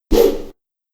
Melee Weapon Air Swing 12.wav